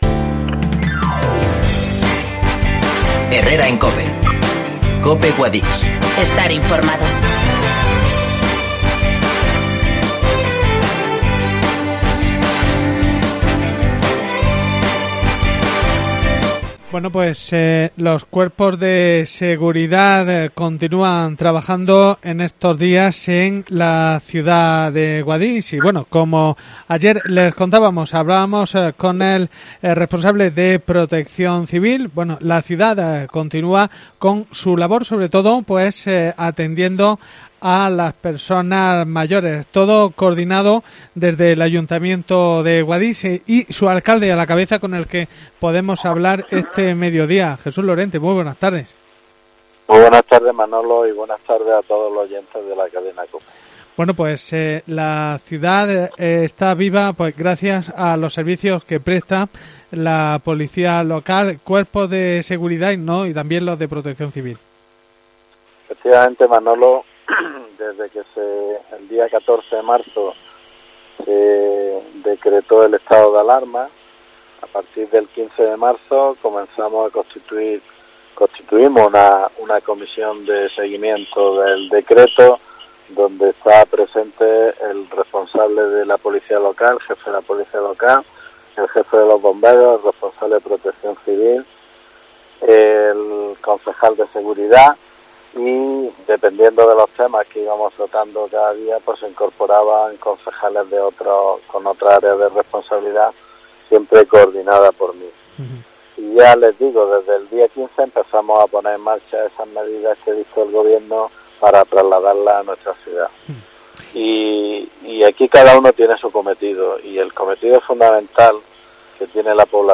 Entrevista: El Alcalde Guadix Jesús Lorente aconsejo a los ciudadanos que no se acerquen a la Biblioteca.